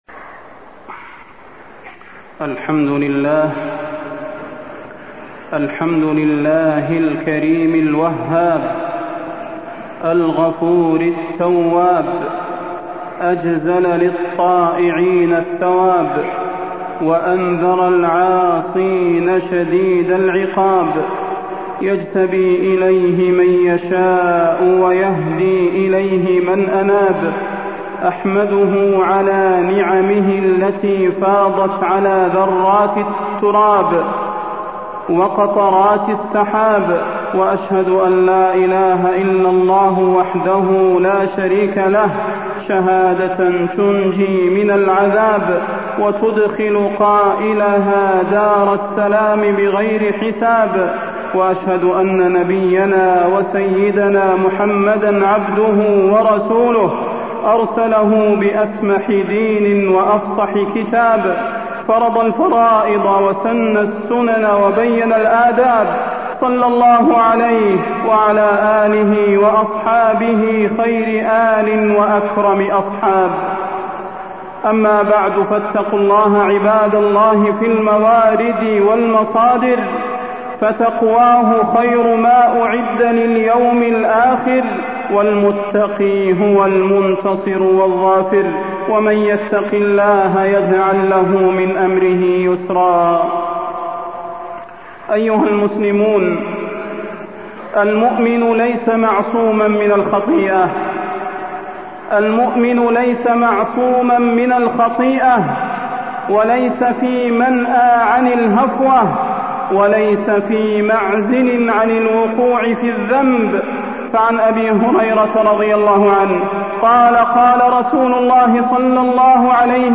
تاريخ النشر ٢٤ شعبان ١٤٢٥ هـ المكان: المسجد النبوي الشيخ: فضيلة الشيخ د. صلاح بن محمد البدير فضيلة الشيخ د. صلاح بن محمد البدير التوبة والاستغفار